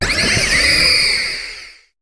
Cri de Tokopiyon dans Pokémon HOME.